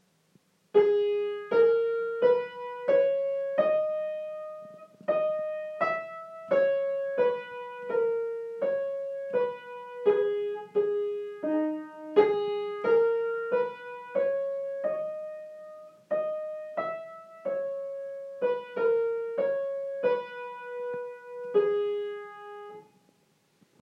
Диктант